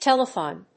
音節tel・e・thon 発音記号・読み方
/téləθὰn(米国英語), téləθ`ɔn(英国英語)/